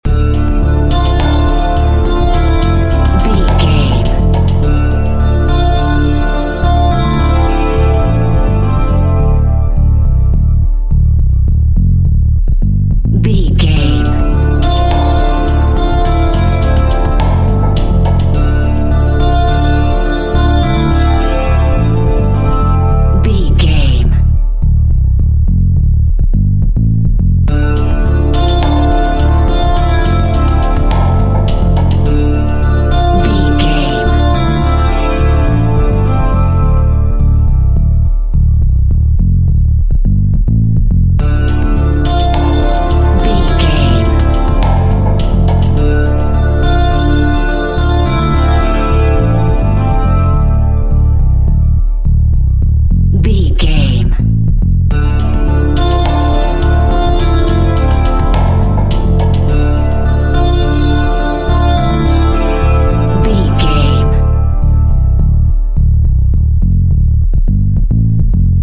Cool and chilled out exotic music to take you away.
Aeolian/Minor
world instrumentals
percussion
congas
bongos
djembe
marimba